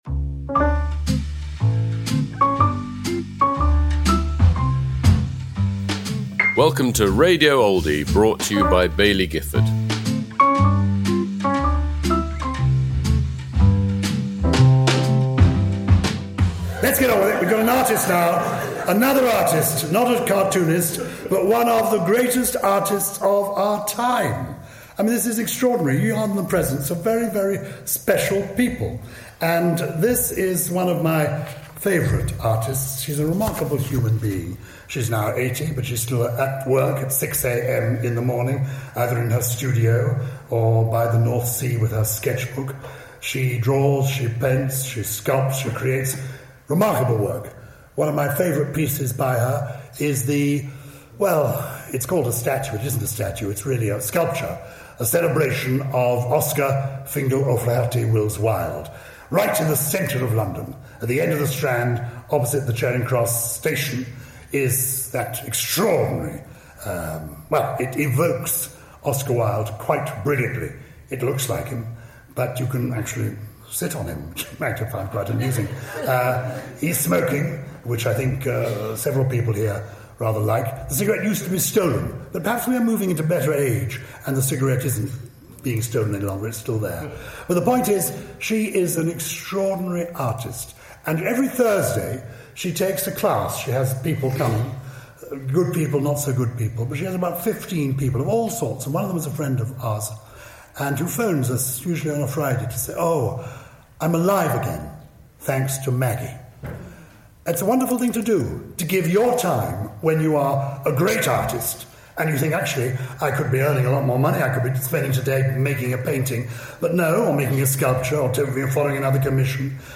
Maggi Hambling at the 2025 Oldie of the Year Awards
Introduced by Gyles Brandreth.